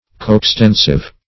Coextensive \Co`ex*ten"sive\, a.